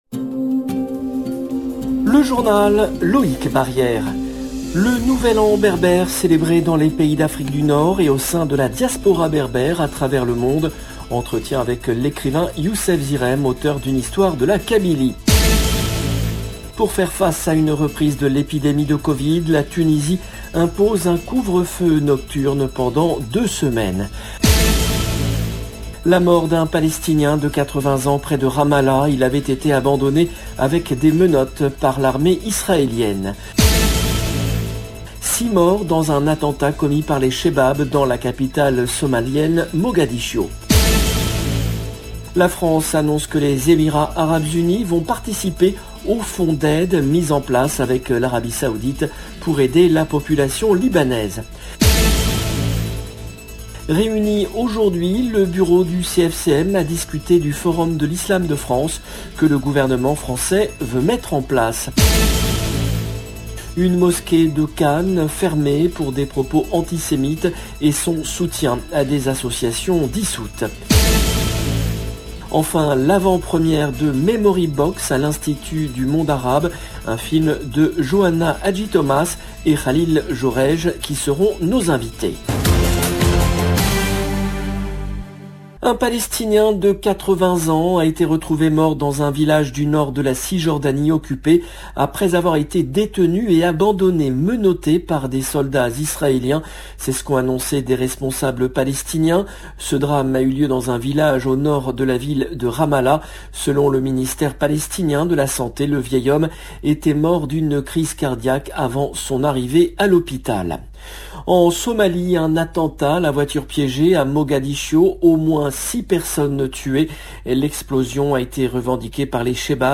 LE JOURNAL DU SOIR EN LANGUE FRANCAISE DU 12/01/2022